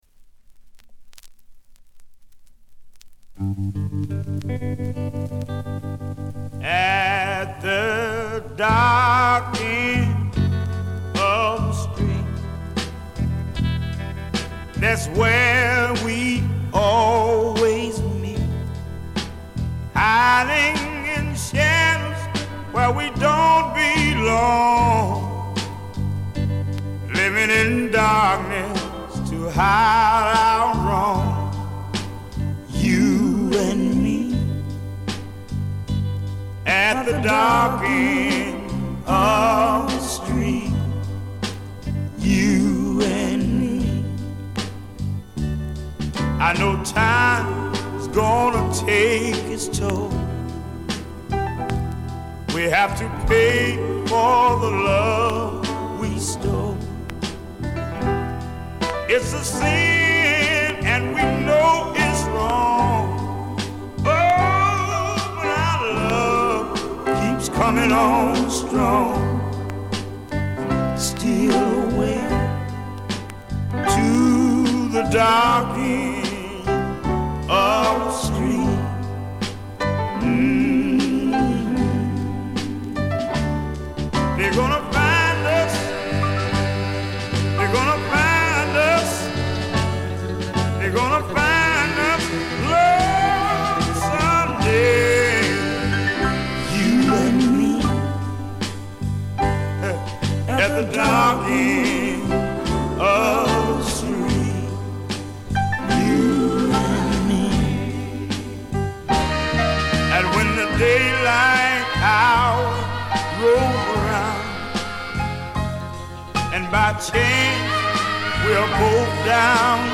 モノラル・プレス。
試聴曲は現品からの取り込み音源です。
※B1-B2連続です。B1エンド直前から曲間の周回ノイズ、その後B2開始後のプツ音をご確認ください。